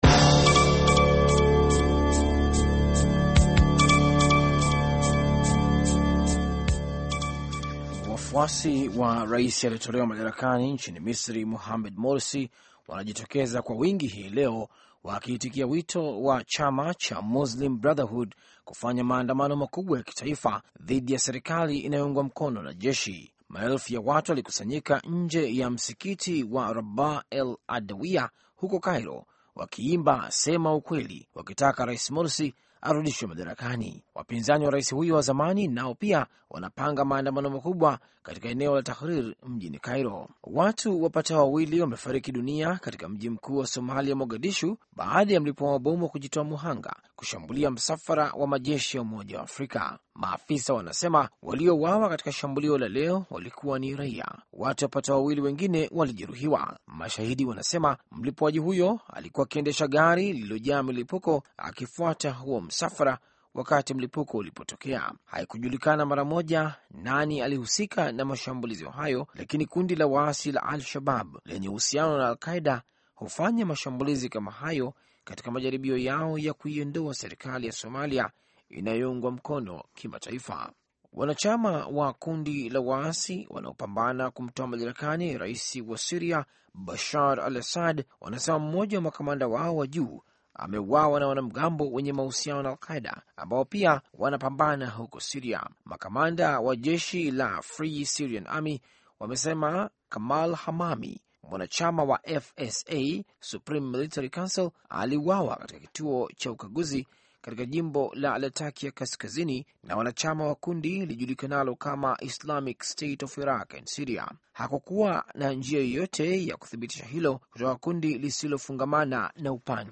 Taarifa Ya Habari VOA Swahili - 6:28